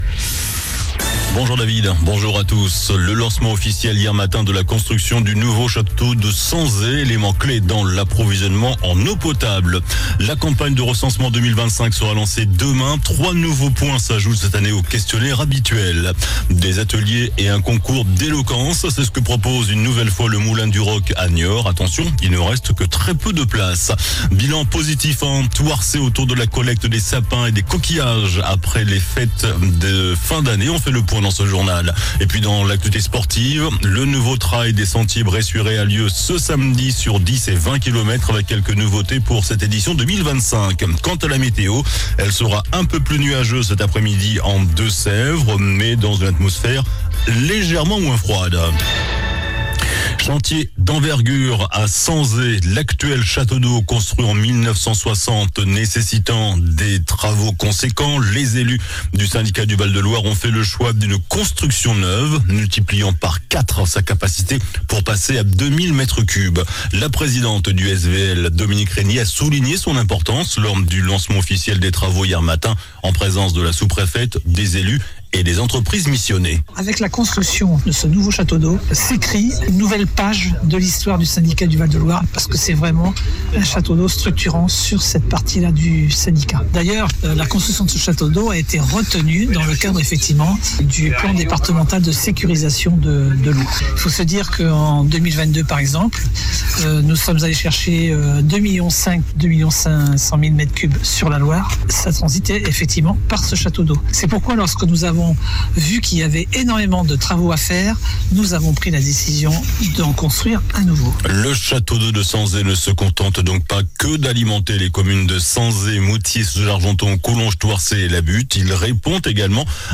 JOURNAL DU MERCREDI 15 JANVIER ( MIDI )